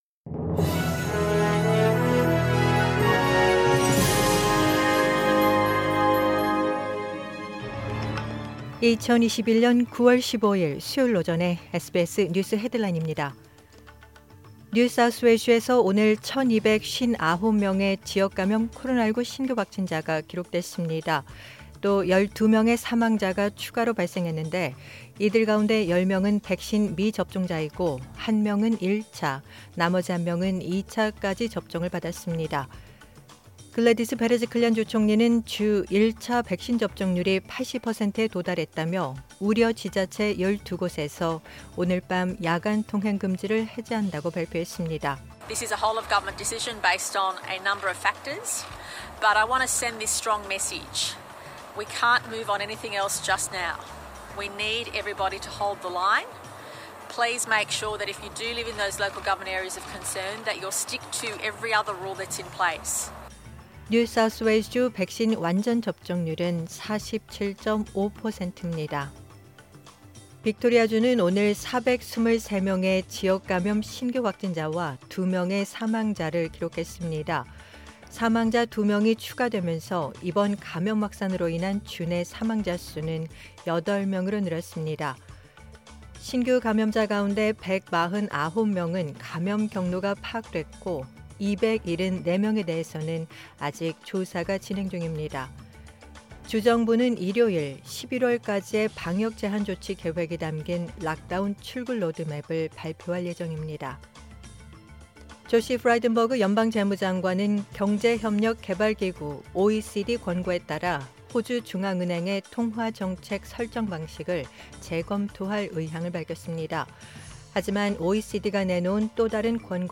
2021년 9월 15일 수요일 오전의 SBS 뉴스 헤드라인입니다.